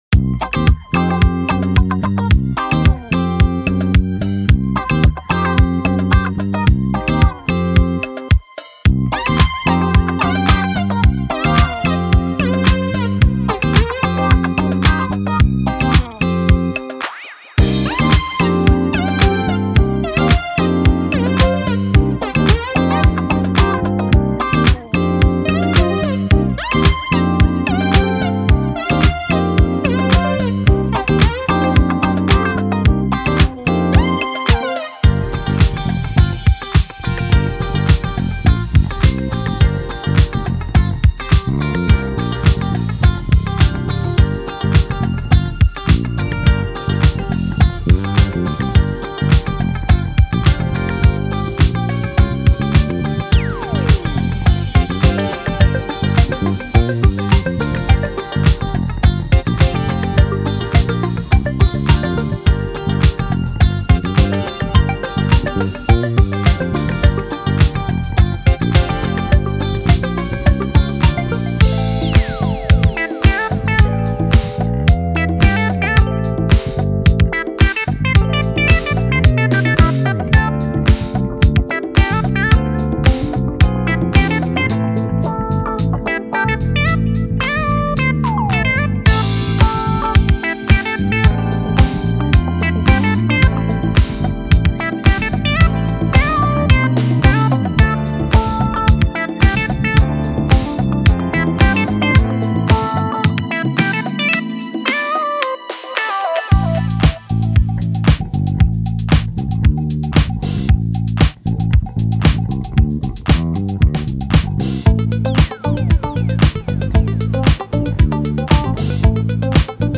Genre:Disco
デモサウンドはコチラ↓
Tempo/Bpm 105-120